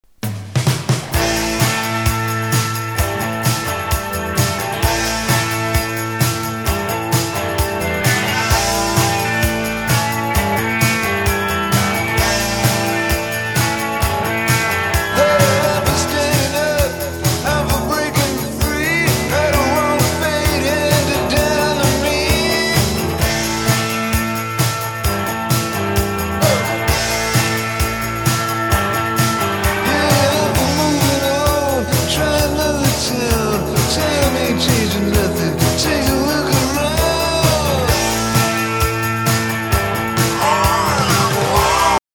アンプエラー もなしが、15v駆動の糸は回転遅いわ ピッチは
揺れるは 18v〜24 の ACアダプ安かったら買うか。